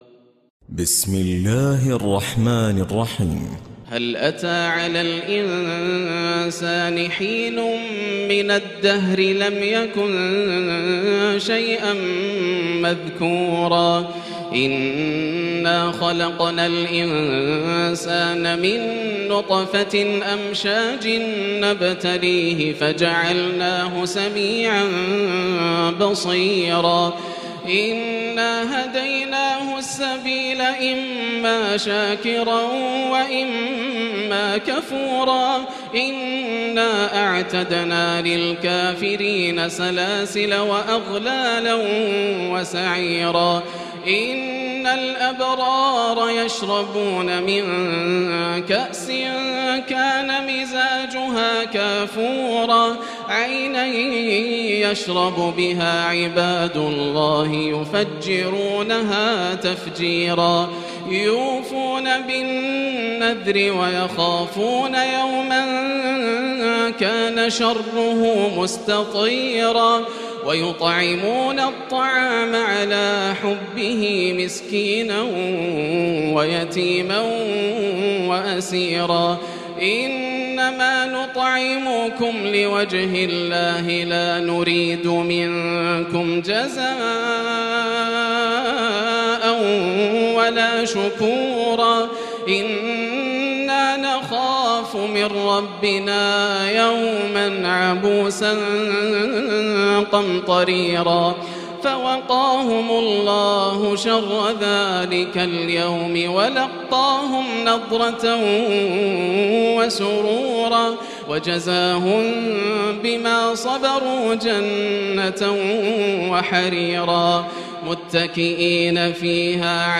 سورة الإنسان من تراويح رمضان 1438هـ > السور المكتملة > رمضان 1438هـ > التراويح - تلاوات ياسر الدوسري